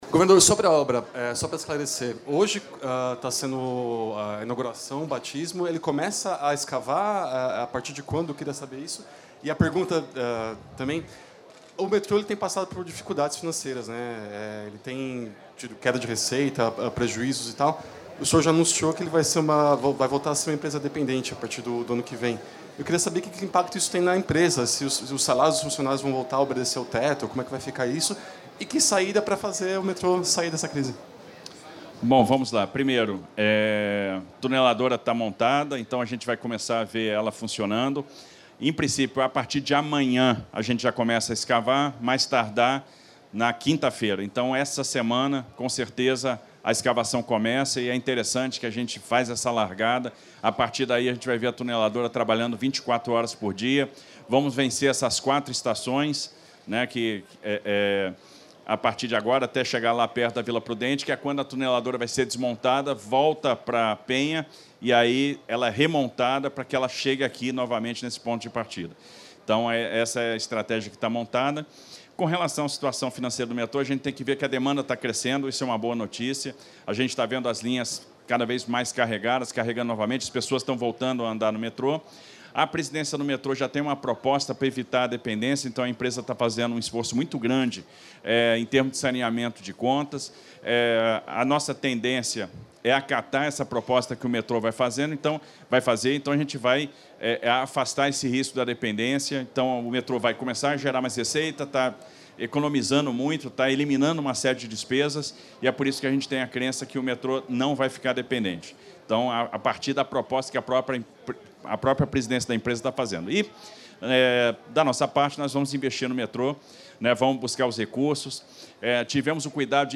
Declaração é do governador Tarcísio de Freitas em entrega do Tatuzão da linha 2; Projeto executivo da zona Leste a Guarulhos foi autorizado pelo Estado; Governador confirmou os planos de expansão …